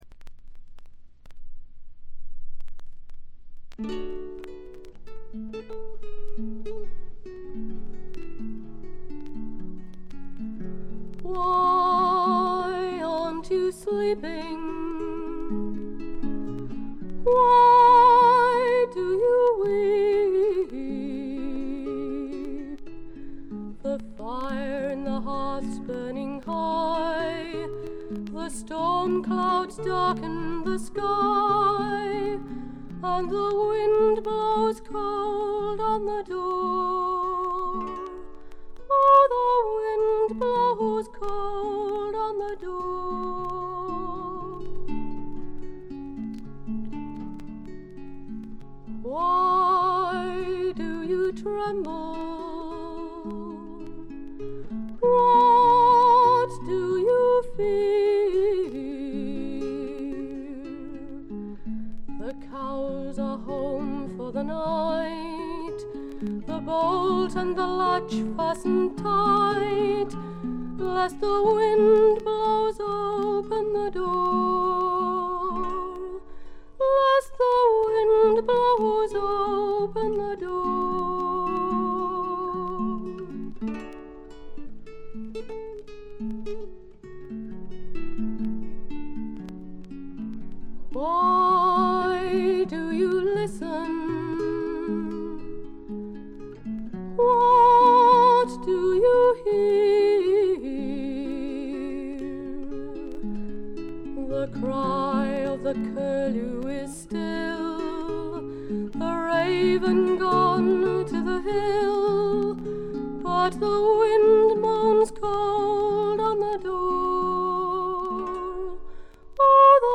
デュエットとかコーラスはあまりなくて曲ごとに交互にソロをとるという構成です。
試聴曲は現品からの取り込み音源です。